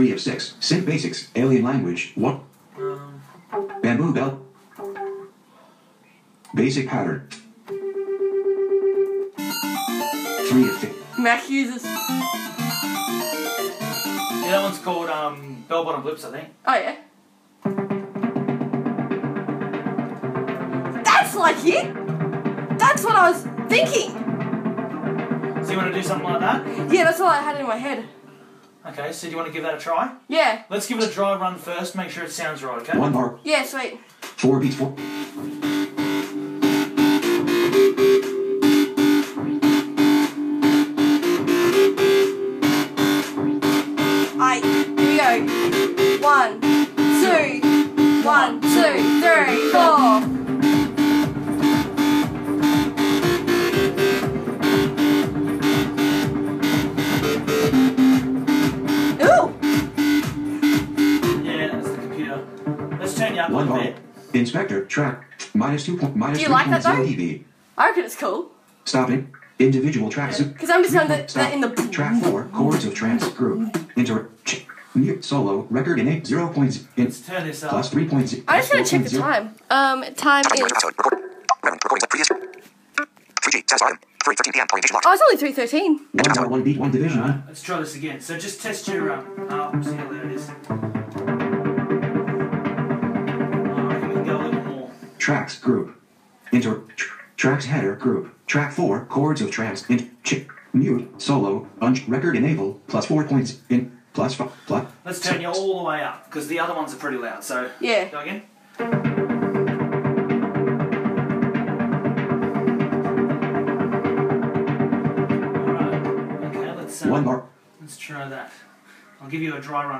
Part 02: there is always a highlight in a recording session, and this was definitely it!!!!